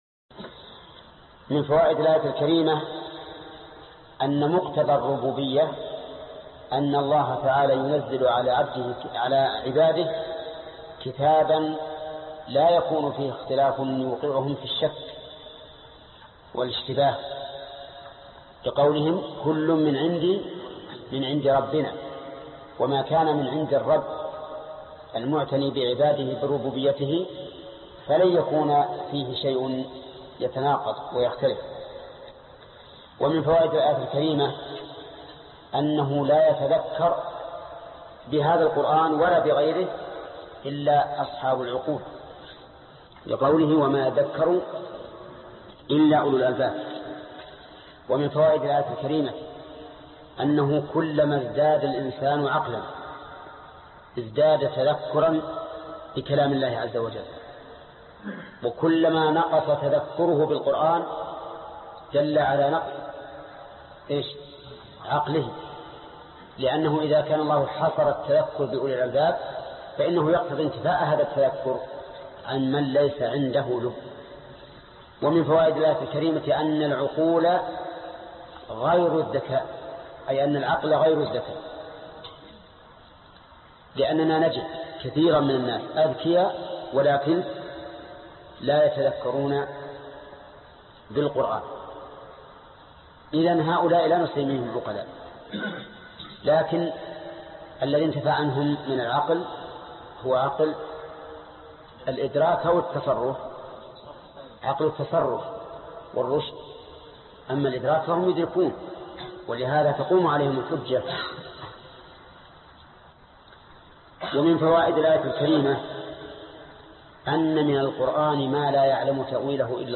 الدرس 14 الآية رقم 8،7 (تفسير سورة آل عمران) - فضيلة الشيخ محمد بن صالح العثيمين رحمه الله